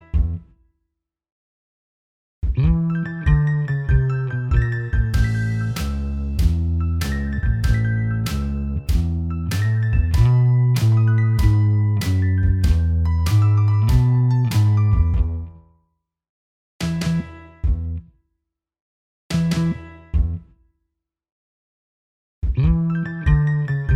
Minus Guitars Pop (1960s) 2:11 Buy £1.50